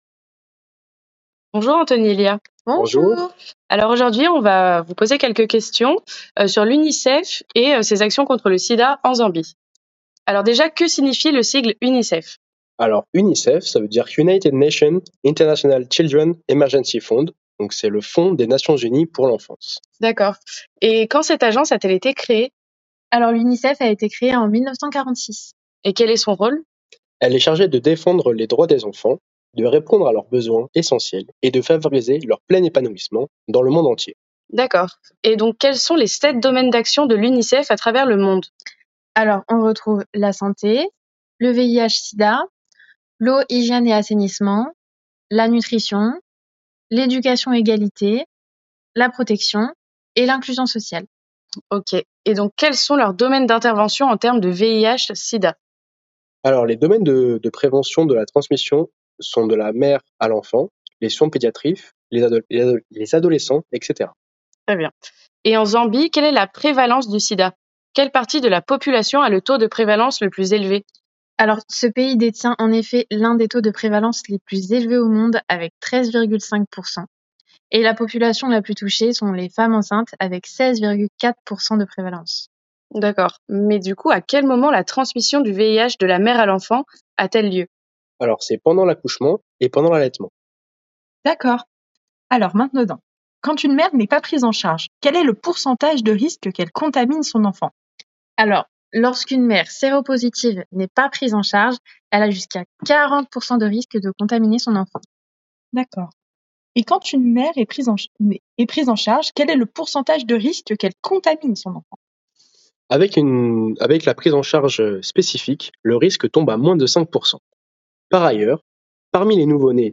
interview-UNICEF.mp3